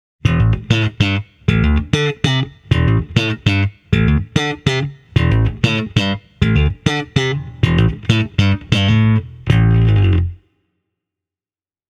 All clips have been recorded with a microphone:
Jazz Bass – played with a plectrum, a little bit of Contour added